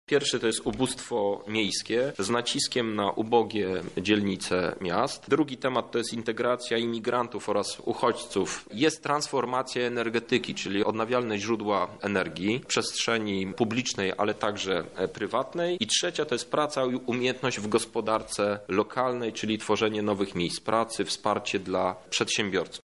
Przewidziano 4 kategorie projektów, mówi Krzysztof Hetman, europoseł.